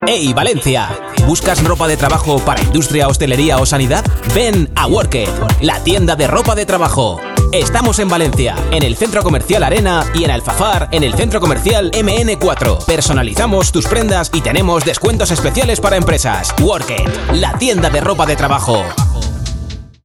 Cuña de radio para tienda de ropa de trabajo.